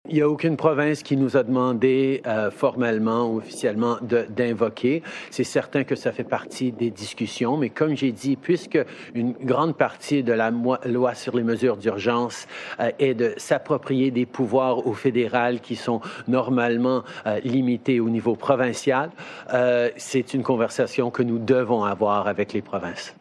Dans son point de presse quotidien sur la situation de la COVID-19, M. Trudeau n'écarte aucune mesure, dont le recours à la loi sur les mesures d'urgence: